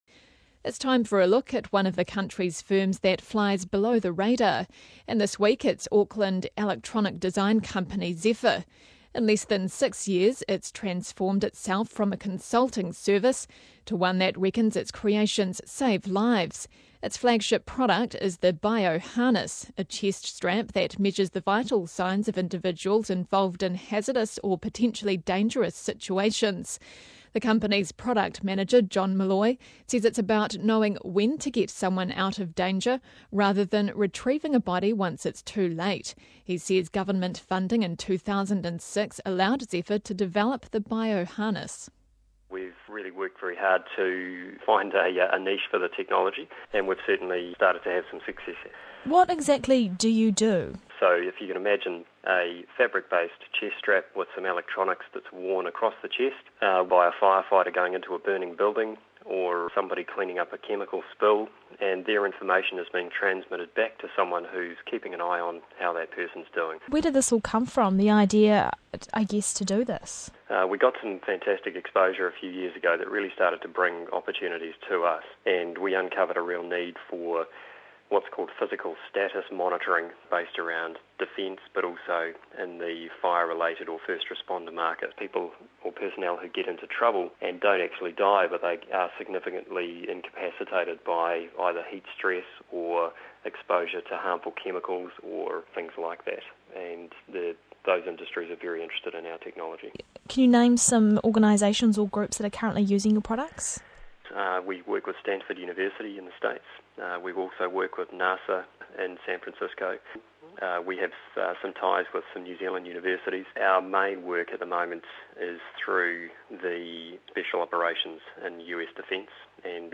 Interview (mp3)